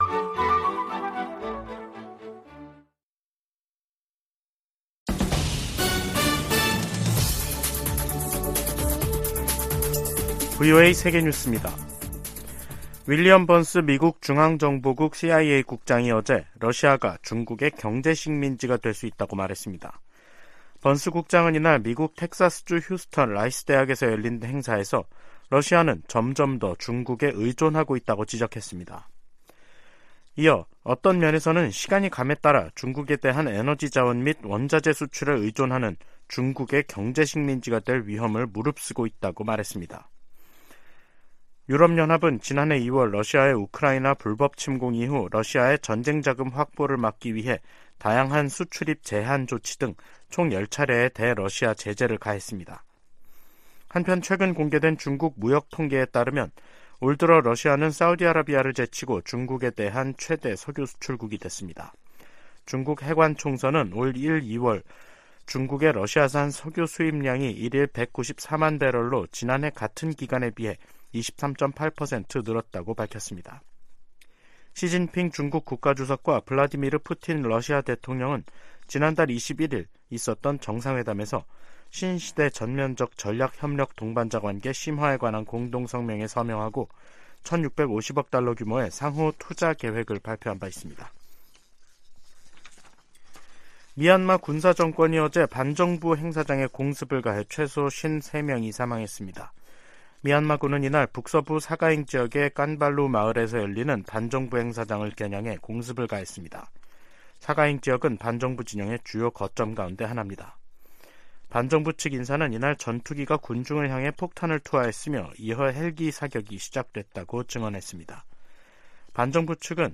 VOA 한국어 간판 뉴스 프로그램 '뉴스 투데이', 2023년 4월 12일 2부 방송입니다. 백악관은 최근 기밀 문건 유출 사건 직후 즉각적인 조치를 취했으며, 법무부 차원의 수사도 개시됐다고 확인했습니다. 미 국방장관과 국무장관은 기밀 유출 사건을 심각하게 받아들이며 철저히 조사하고 있다고 밝혔습니다. 미국은 대북 감시에 정찰 자산을 총동원하고 있으며, 미한일 3국 협력이 시너지를 낼 것이라고 미국 전문가들이 전했습니다.